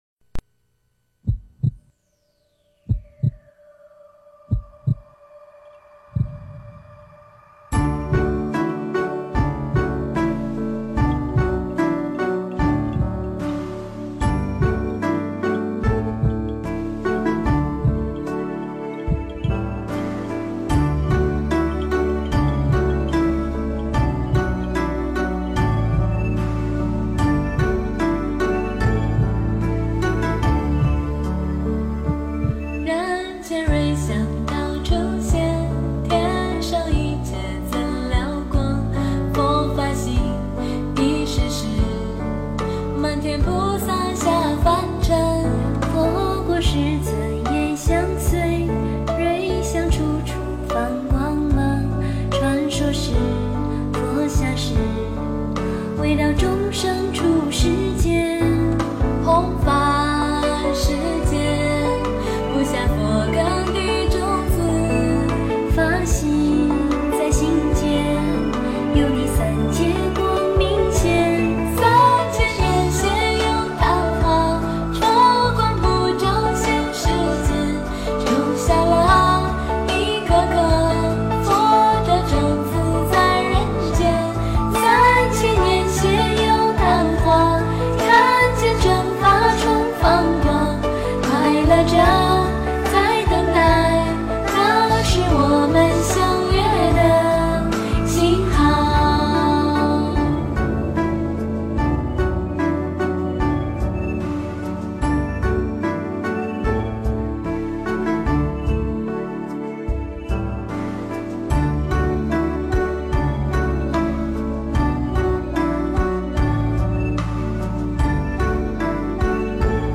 优昙花 诵经 优昙花--佛教音乐 点我： 标签: 佛音 诵经 佛教音乐 返回列表 上一篇： 永恒是佛性 下一篇： 遇上你是我的缘 相关文章 Asian Rhapsody亚洲狂想曲--禅定音乐 Asian Rhapsody亚洲狂想曲--禅定音乐...